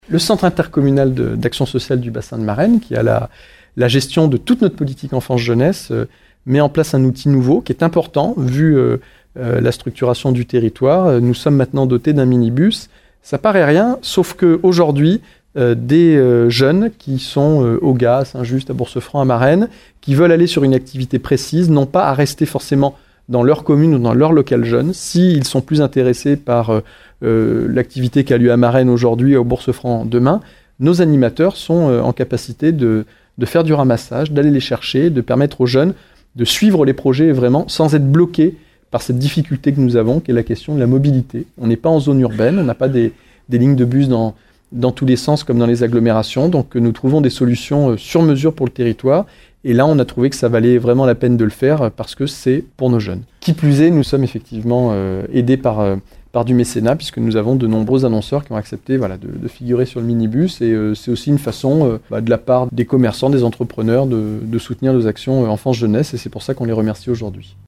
On écoute le président de la CdC du Bassin de Marennes Mickaël Vallet :
Lors de l’inauguration hier.